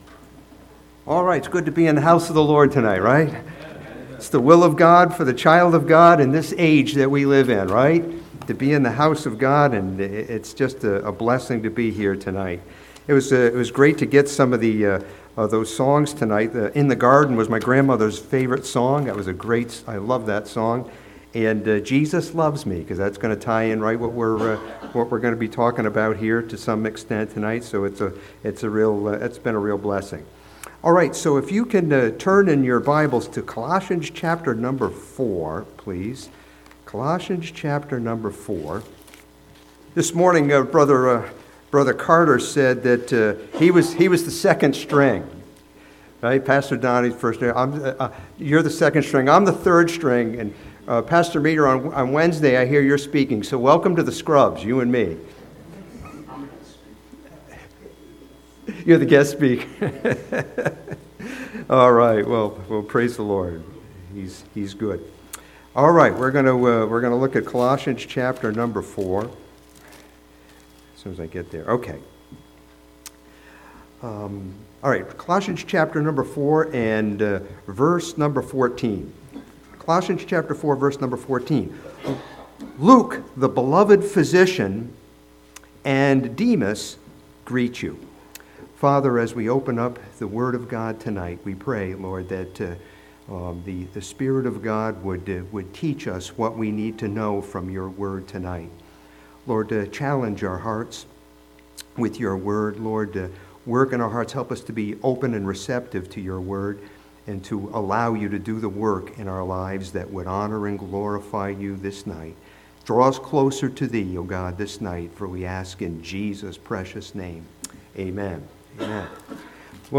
This sermon from Colossians chapter 4 studies Demas as a portrait of misplaced love.